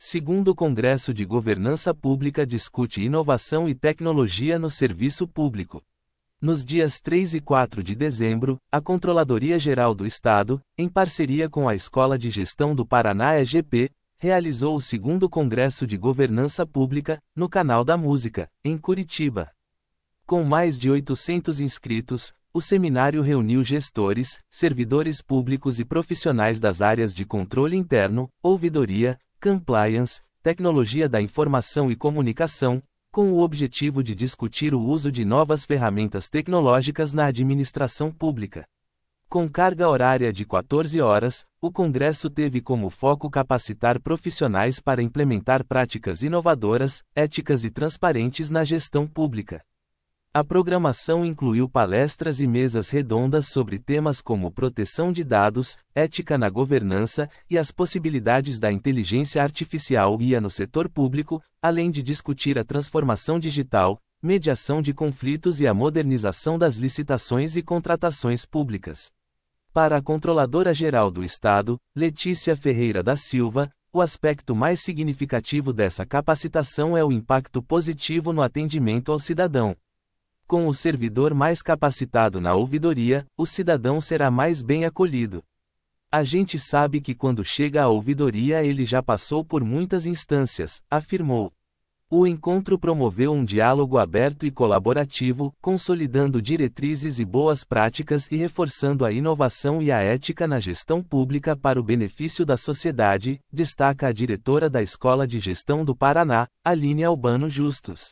audionoticia_2o_congresso_de_governanca.mp3